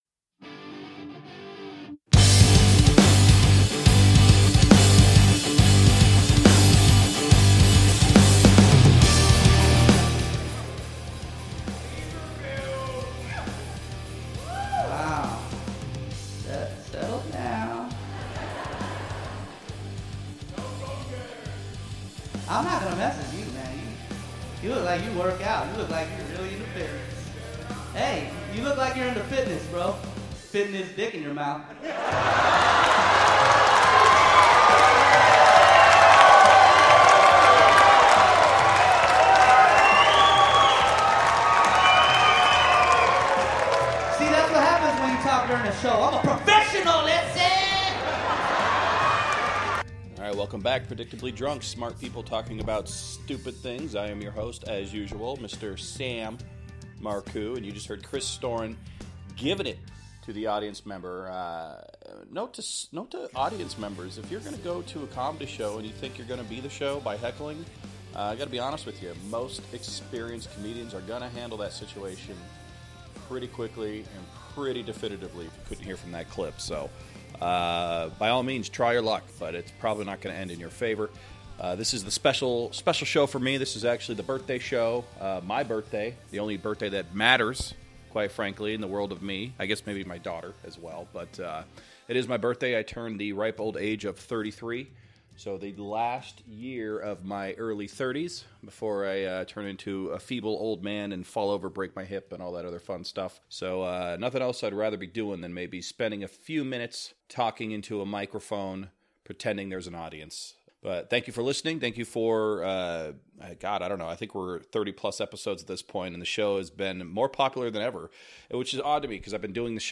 he is sitting in his studio ranting and raving like a lunatic